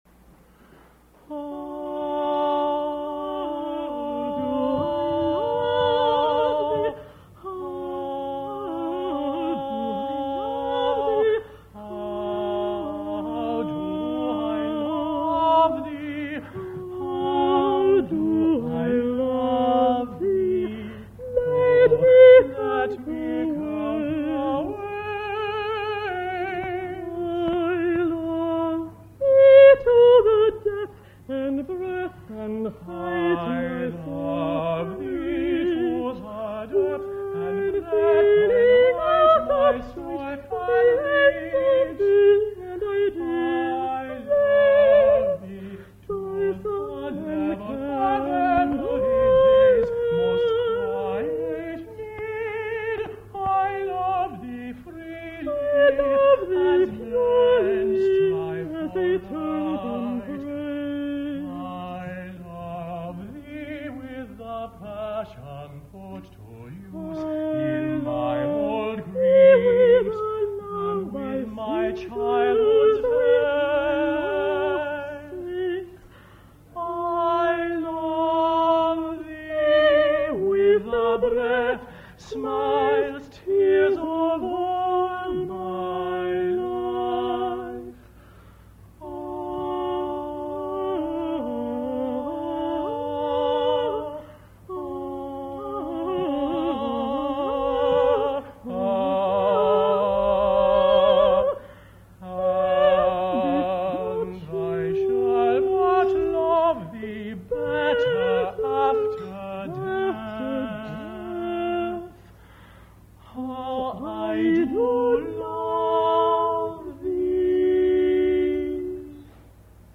alto & tenor duet